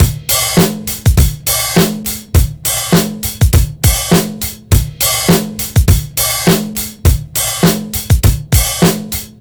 TR BEAT 2 -R.wav